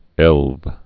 (ĕlv)